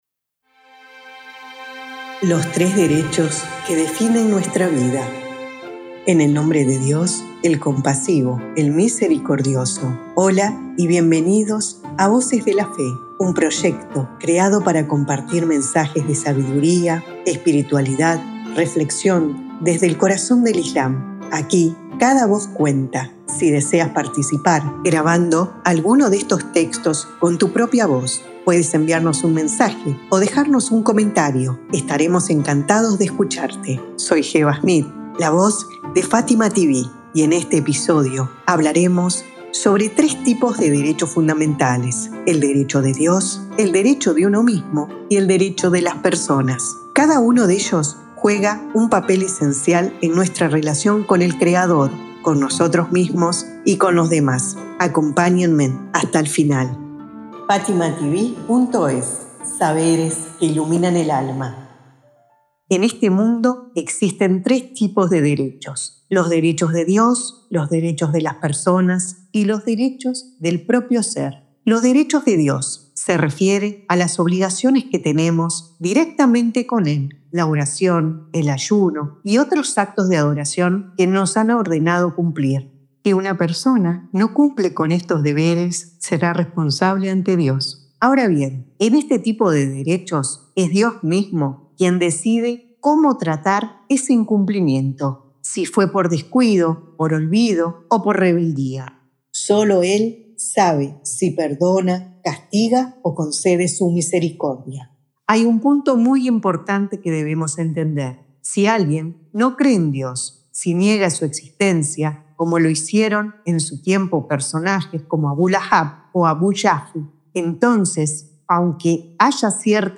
En este episodio de Voces de la Fe, reflexionamos sobre tres tipos de derechos: los derechos de Dios, los derechos de uno mismo y los derechos de los demás. Descubre cómo estos tres pilares pueden transformar nuestra vida, guiarnos hacia la justicia y acercarnos a Dios. 🎙 Locutora: